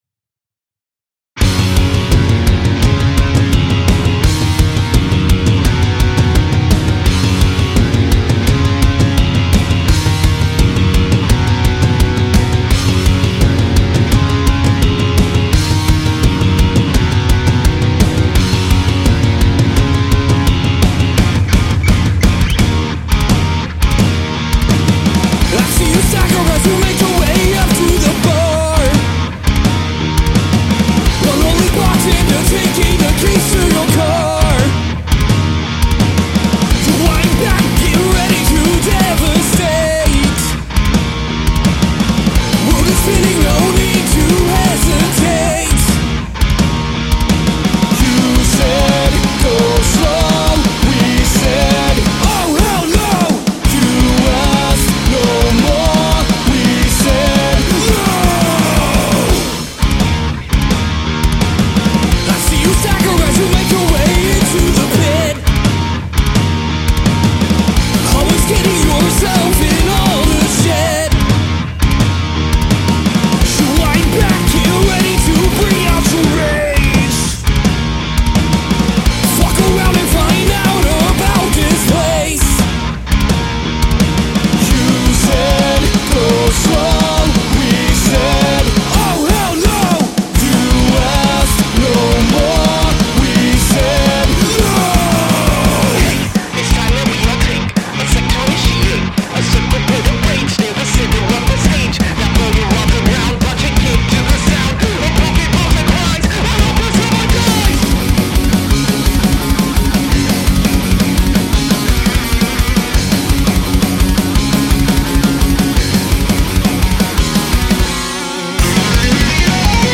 vocals and guitar
bass
back-up vocals and guitar
drums and back-up vocals